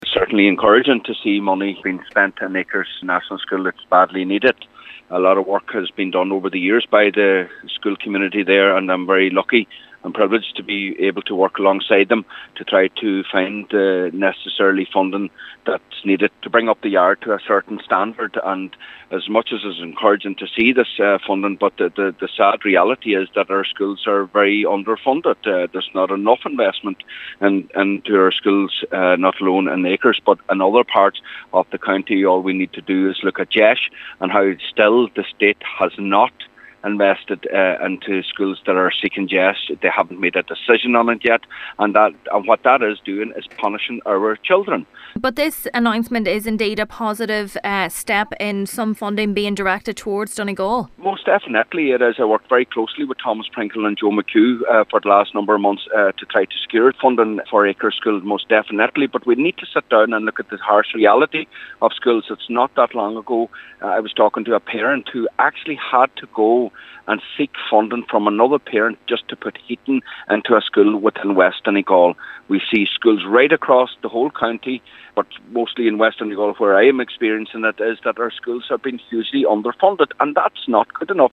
Cathaoirleach of Glenties Municipal District Councillor Micheal Cholm Mac Giolla Easbuig has welcomed the funding but says there is still a long way to go to resurrect the under funding suffered by schools in Donegal: